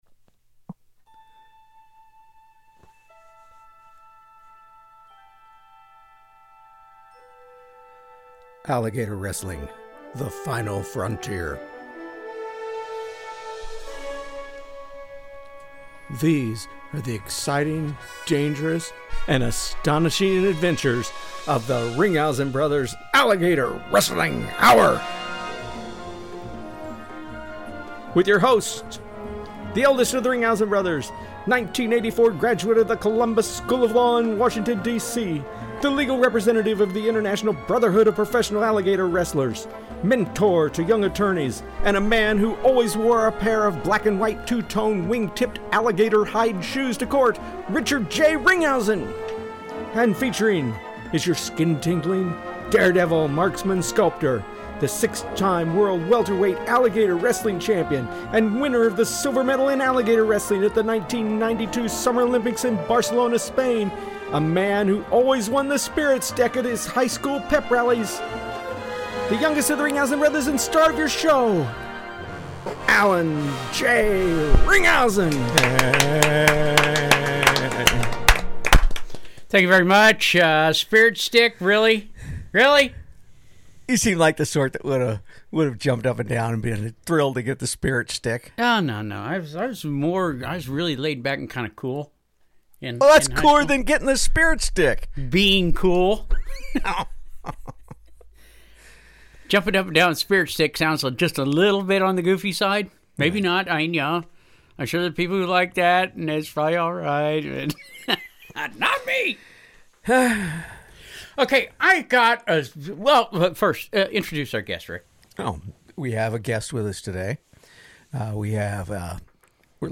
being stalked by wolves... and interview a Real, Live, Park Ranger!